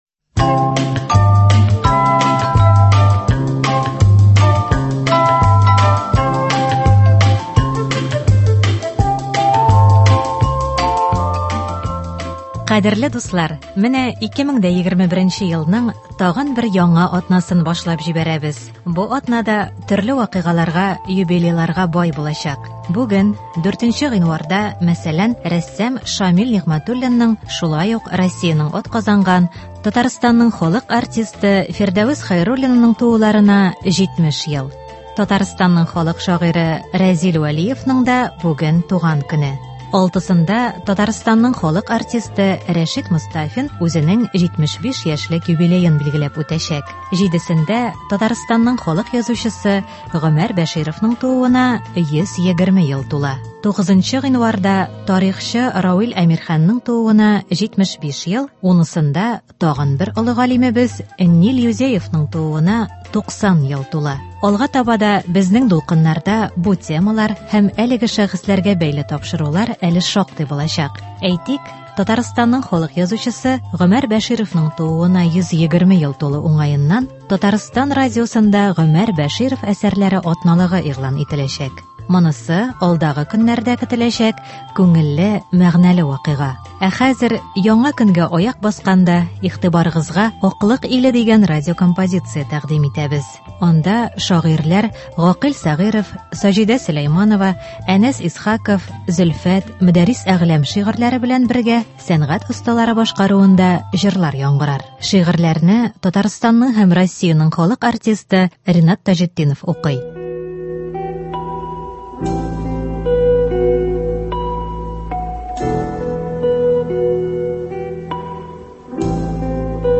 Әдәби-музыкаль композиция.